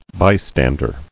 [by·stand·er]